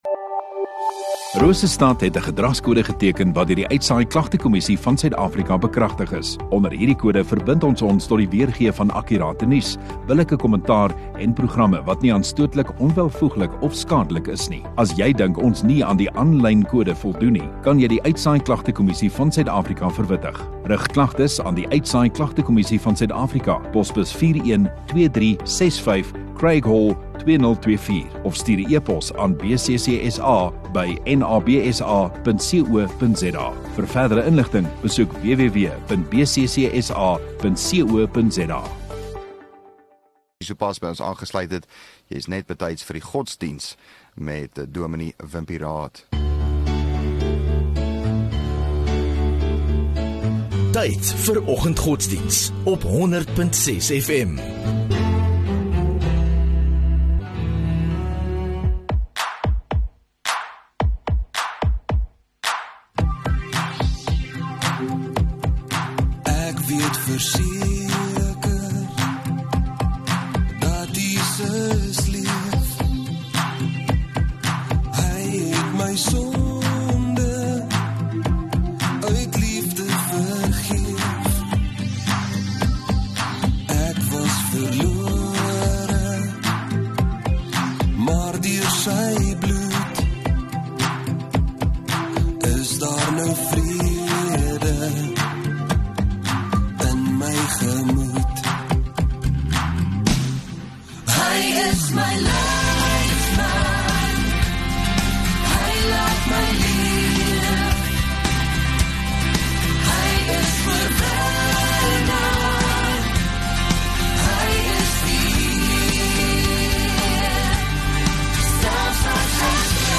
18 Feb Dinsdag Oggenddiens